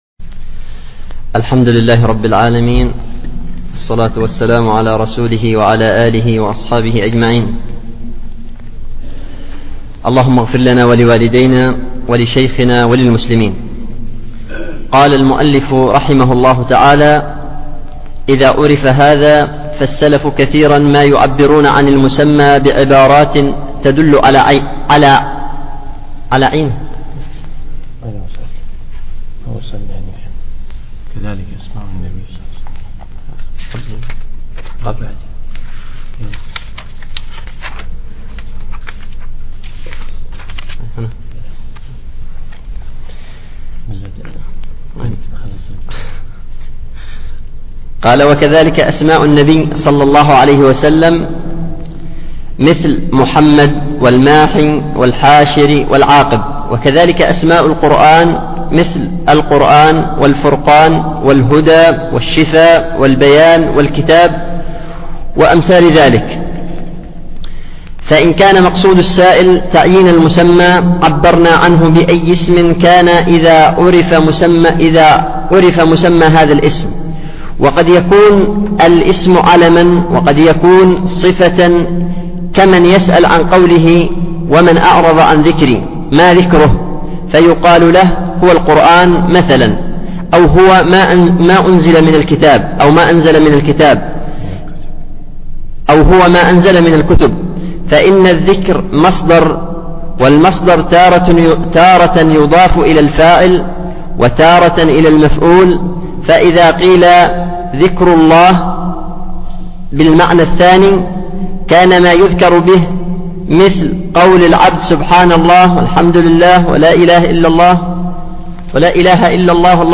الشرح في شهر 9 عام 2011 في دورة الخليفة الراشد الخامسة
الدرس الثالث